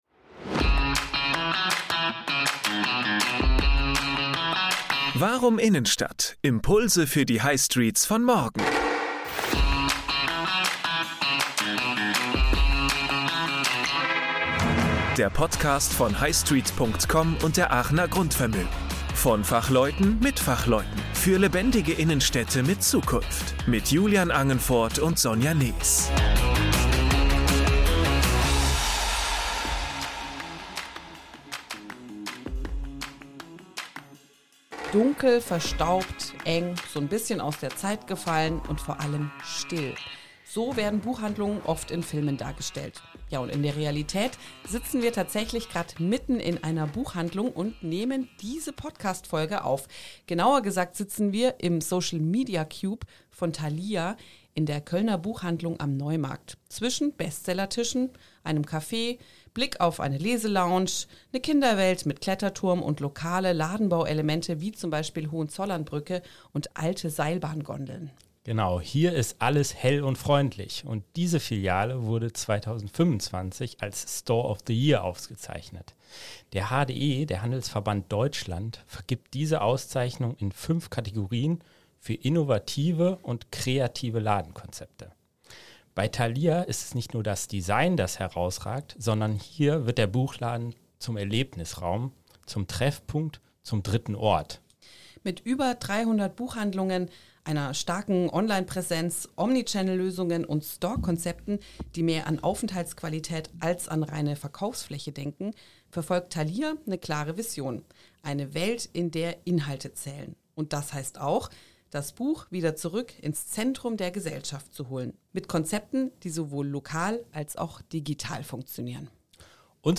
Aufgezeichnet im „Store of the Year 2025“ – direkt im laufenden Betrieb der Kölner Thalia-Filiale